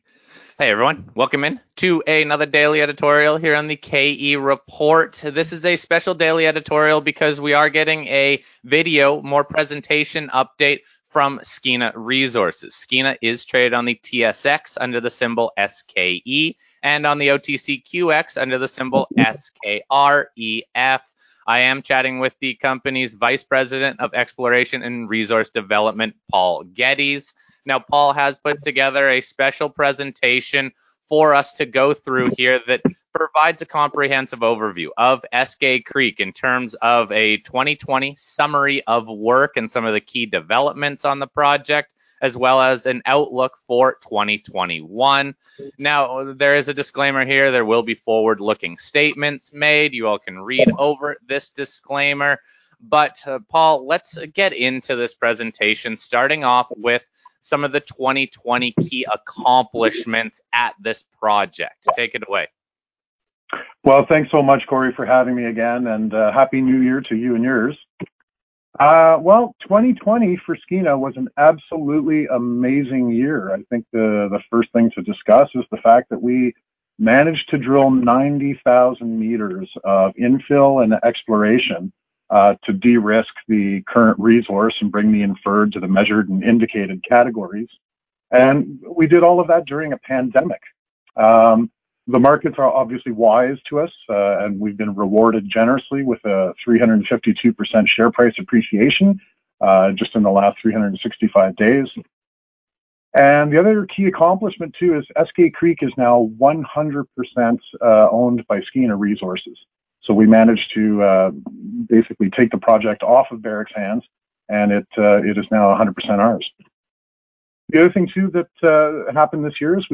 Skeena Resources – A Special Video Interview – 2020 drill recap and 2021 exploration plans – Korelin Economics Report
Audio only interview